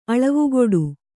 ♪ aḷavugoḍu